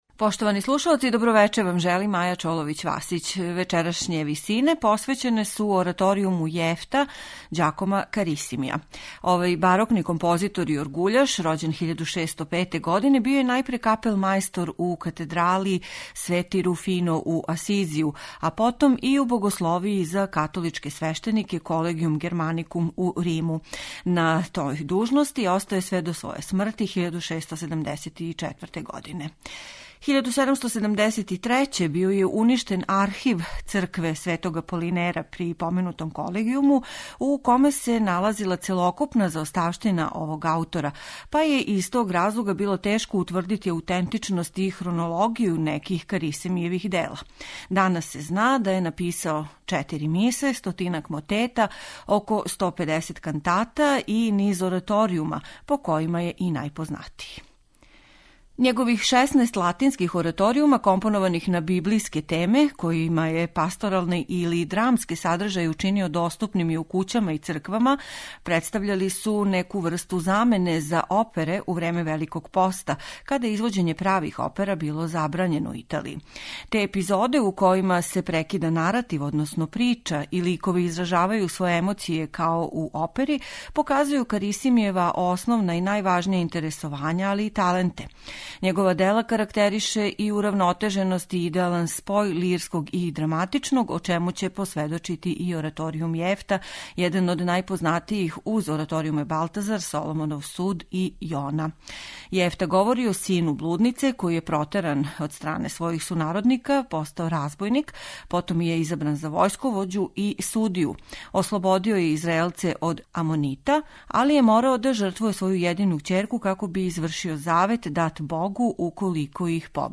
Ораторијум „Јефта' Ђакома Карисимија
Један од најпознатијих латинских ораторијума познатог италијанског ранобарокног мајстора, слушаћете у извођењу ансамбла Le Parliament de Musique .